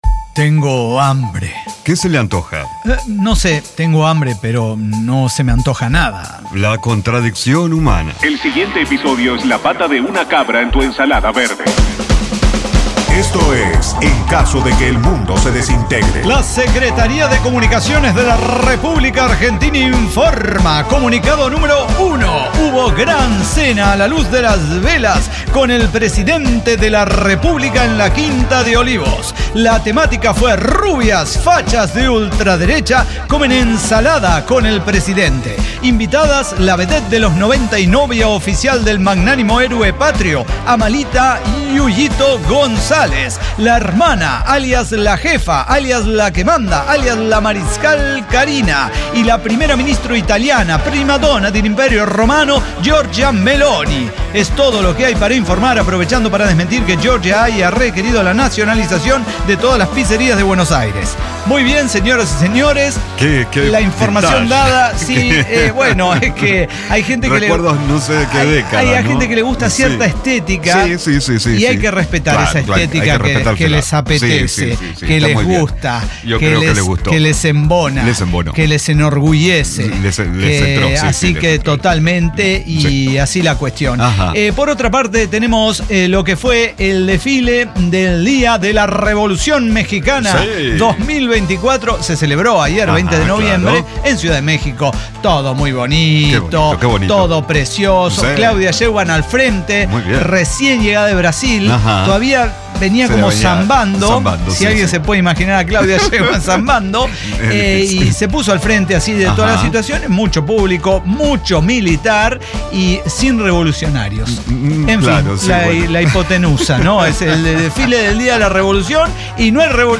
El Cyber Talk Show
Diseño, guionado, música, edición y voces son de nuestra completa intervención humana.